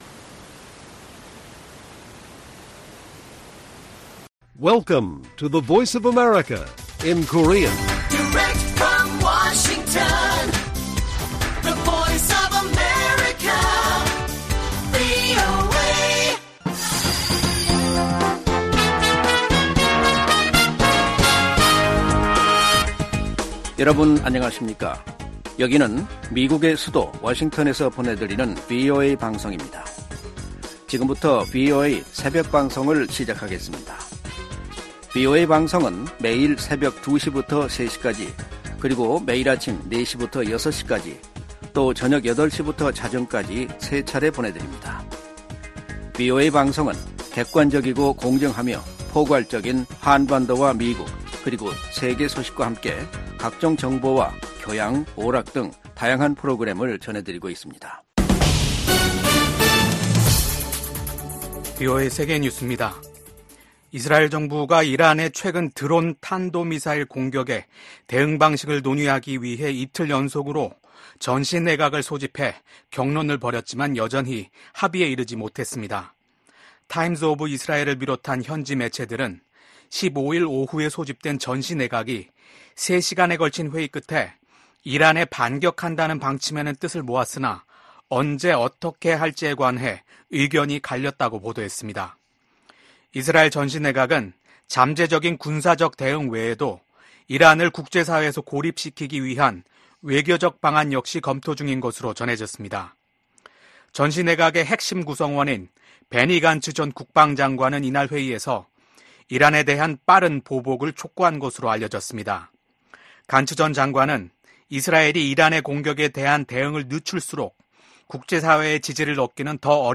VOA 한국어 '출발 뉴스 쇼', 2024년 4월 16일 방송입니다. 미국 유엔대표부 공사참사관은 러시아가 북한 무기 불법 조달을 은폐하기 위해 대북제재 전문가패널 임기 연장을 거부했다고 비판했습니다. 북한의 미사일과 발사 플랫폼 다각화로 미국과 동맹에 대한 위협이 가중되고 있다고 미 국방부 관리가 밝혔습니다.